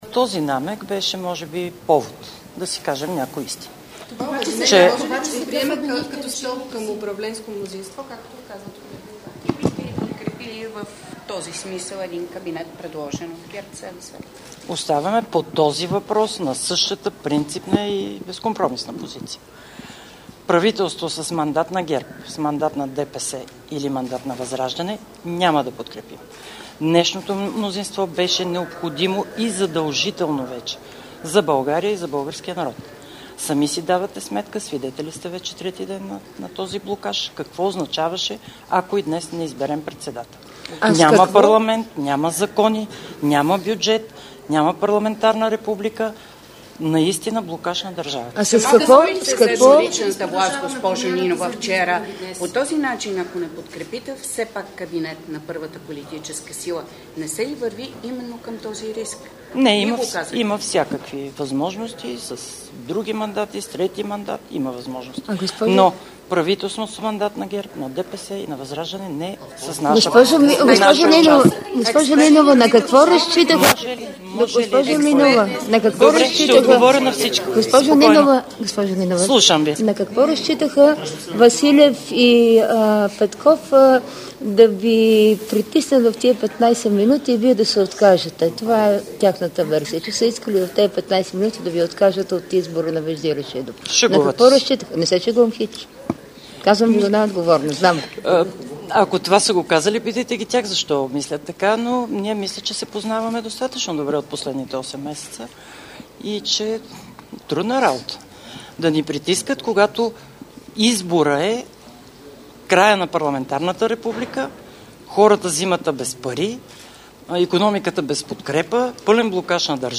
Briefing Kornelia Ninova 14 50H 21 10 22